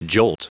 Prononciation du mot jolt en anglais (fichier audio)
Prononciation du mot : jolt